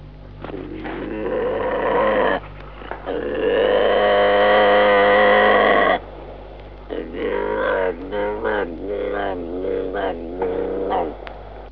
click on the toad for a sound click on the bobcat for sound continue back
tpbobcatsound1.wav